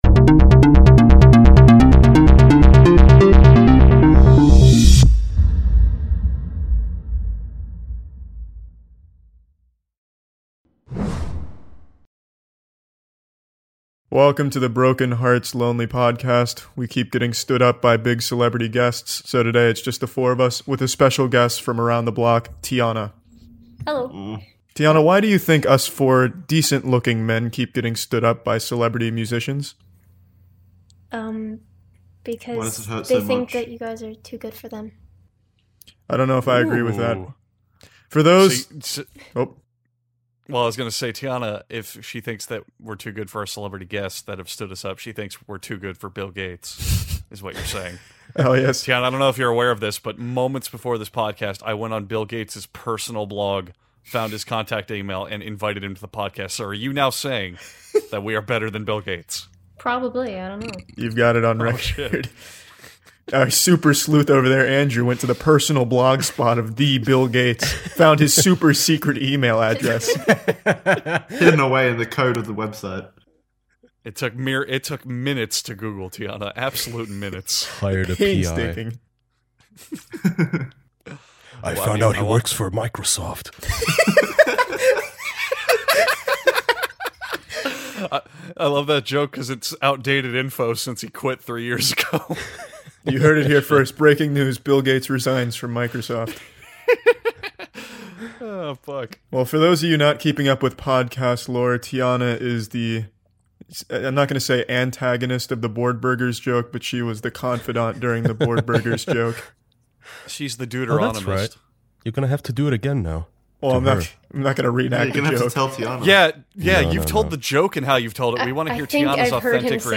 Four close man friends gather around to talk to their closest woman friend.